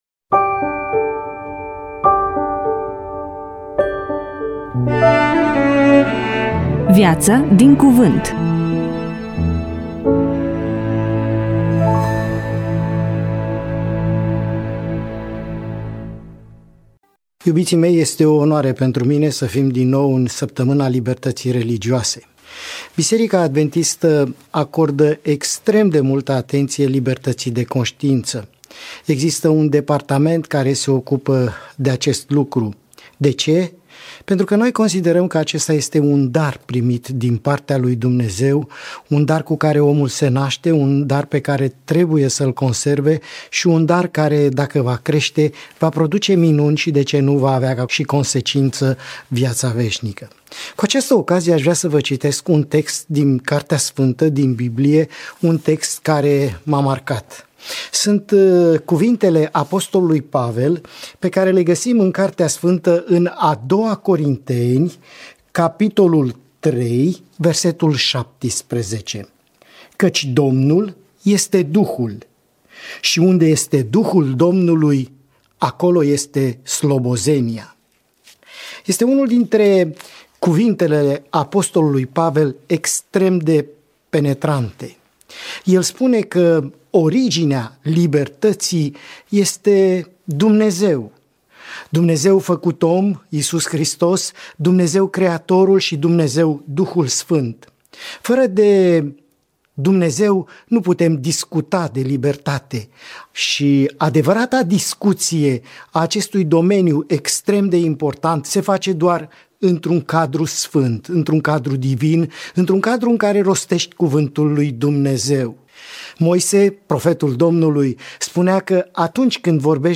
EMISIUNEA: Predică DATA INREGISTRARII: 18.04.2026 VIZUALIZARI: 13